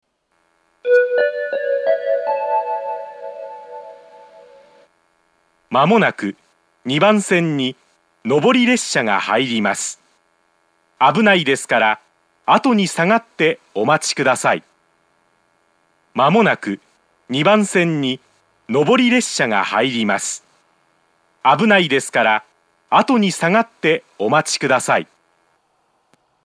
２番線接近放送
iwama-2bannsenn-sekkinn3.mp3